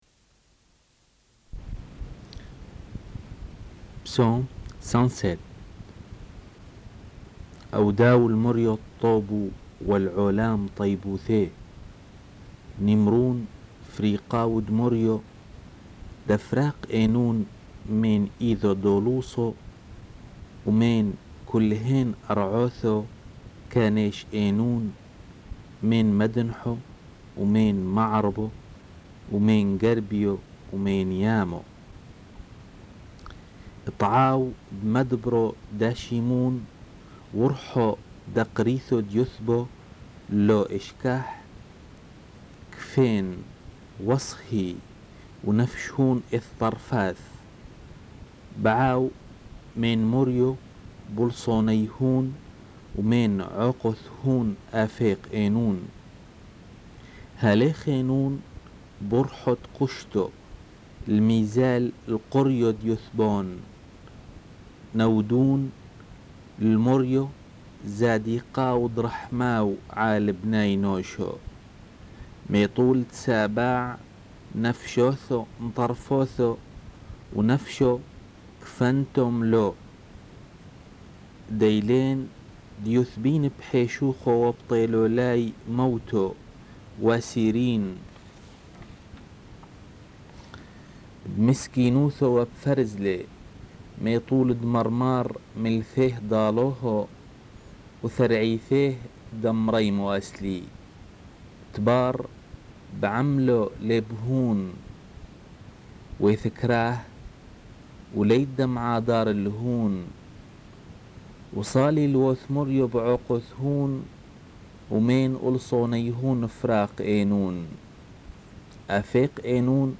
Enregistrement de la lecture des Psaumes (version syriaque)